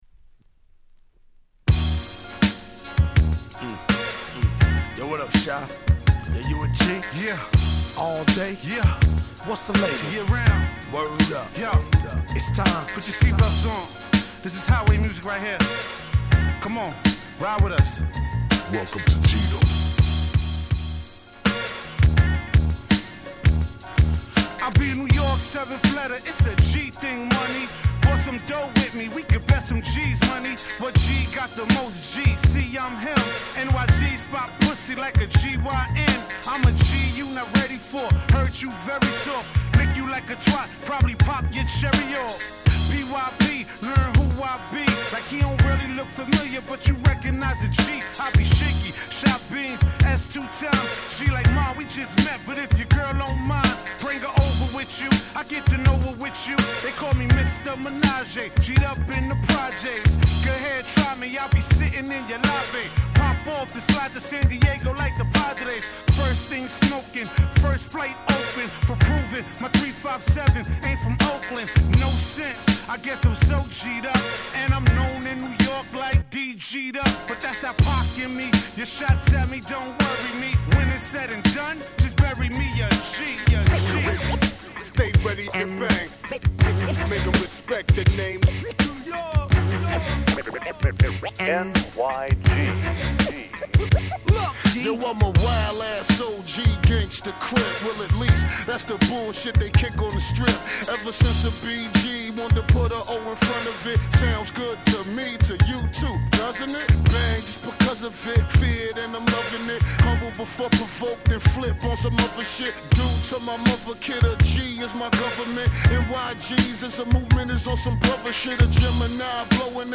08' Suoer Dope Hip Hop !!